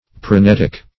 Search Result for " parenetic" : The Collaborative International Dictionary of English v.0.48: Parenetic \Par`e*net"ic\, Parenetical \Par`e*net"ic*al\, a. [Gr. parainetiko`s: cf. F. par['e]n['e]tique.]